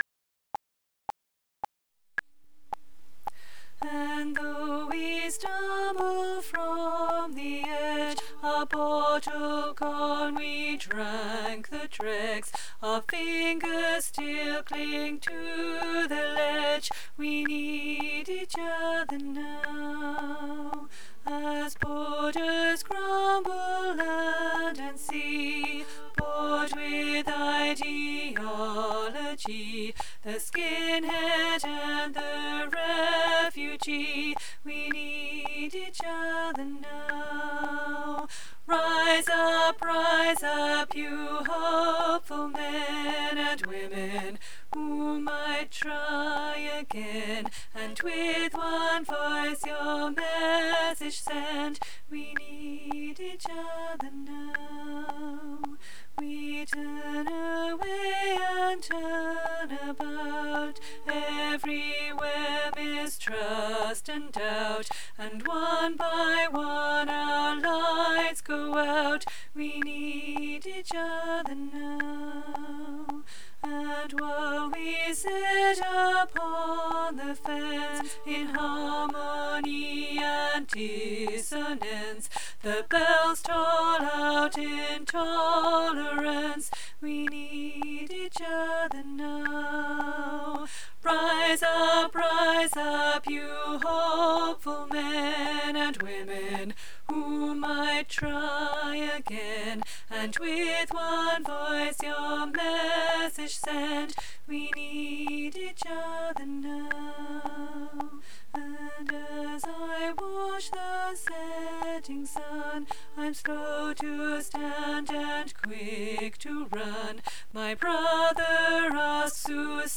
We Need Each Other Now BASS